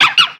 Cri de Ponchiot dans Pokémon X et Y.